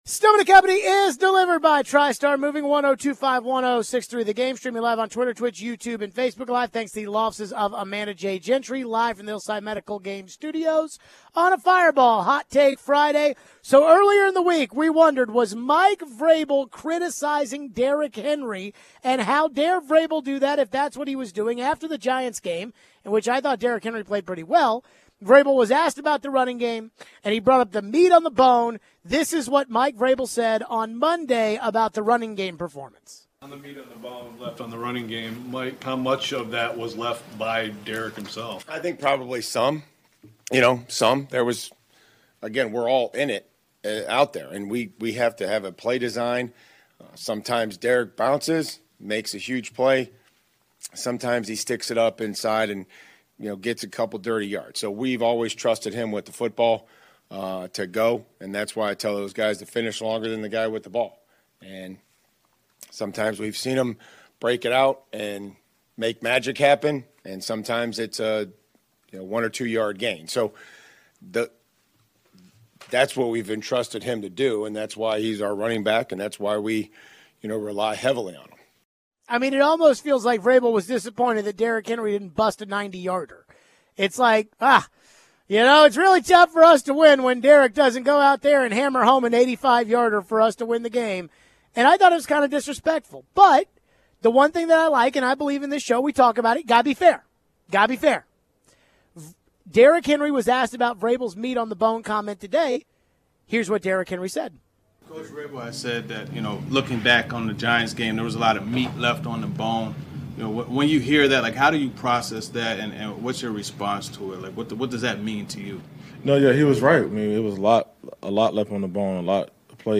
How big of a game do we think Henry and the run game need to be vs BUF? We take your phones.